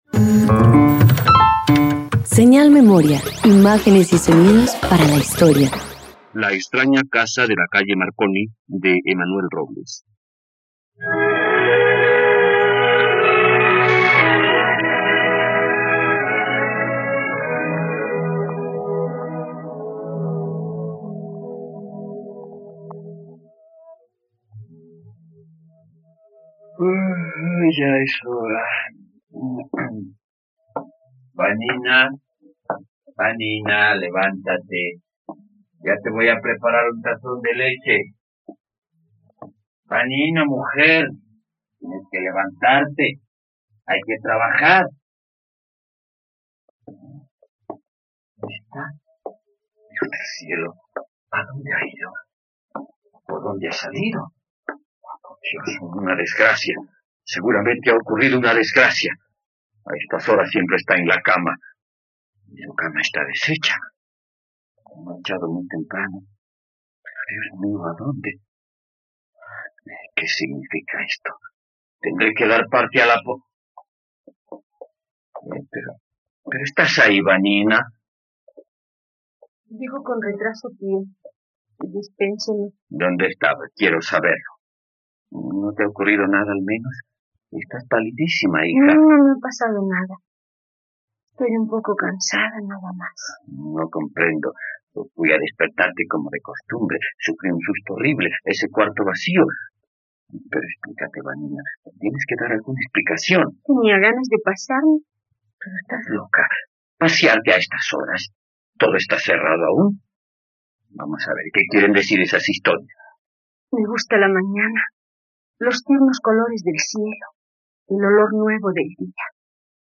La extraña casa de la calle Marconi - Radioteatro dominical | RTVCPlay
Sinopsis Con una adaptación para la radio, esta obra es del autor francés Emmanuel Roblès.